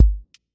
QA Listening Test boom-bap Template: boom_bap_drums_a
Test brief: Boom bap pocket study with drifting warm haze, evolving motion, and soft body
Compose a deterministic boom bap pocket study with drifting warm haze, evolving motion, and soft body.
• voice_kick_808
• voice_snare_boom_bap
• voice_hat_rimshot